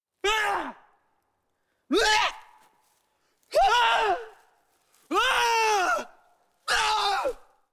gameover.mp3